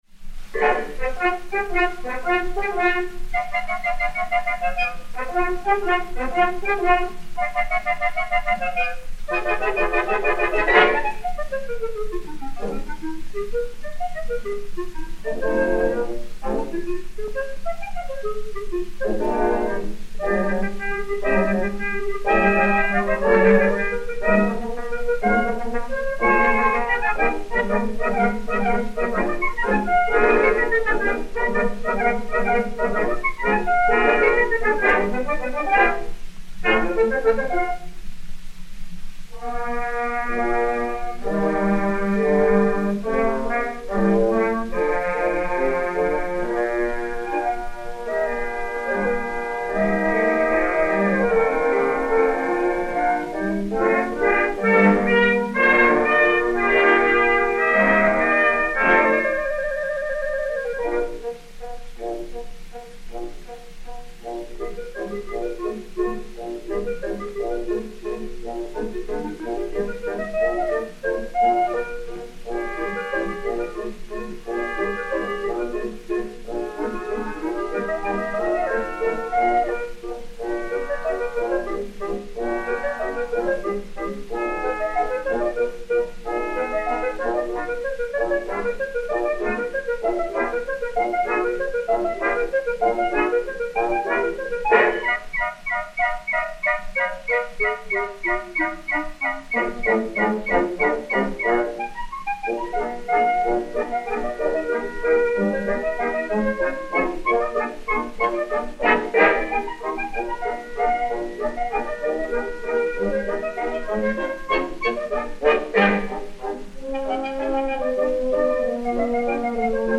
Pathé saphir 80 tours n° 6702, mat. 7263 et 7269, enr. le 20 octobre 1924